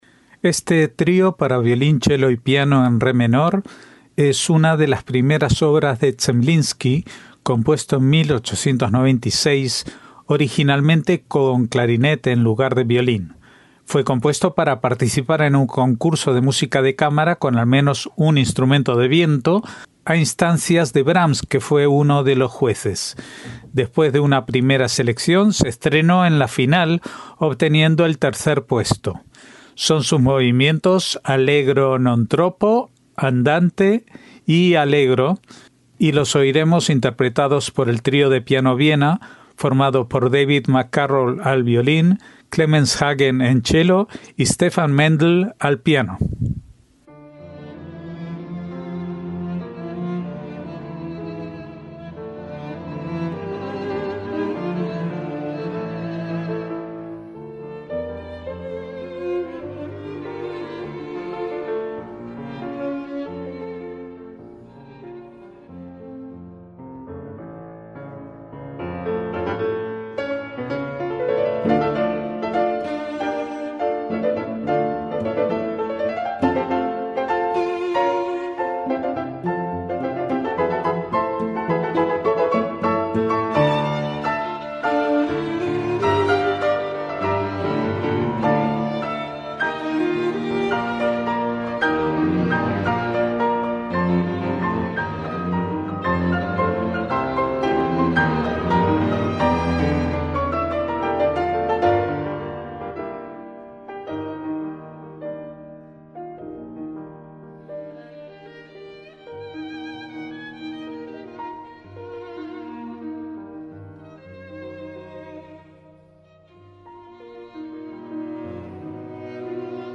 ZEMLINSKY 150 - Este Trío para violín, chelo y piano en re menor es una de las primeras obras de Zemlinsky, compuesto en 1896, originalmente con clarinete, en lugar de violín. Fue compuesto para participar en un concurso de música de cámara con al menos un instrumento de viento, a instancias de Brahms, que fue uno de los jueces.
Son sus movimientos Allegro non troppo, Andante y Allegro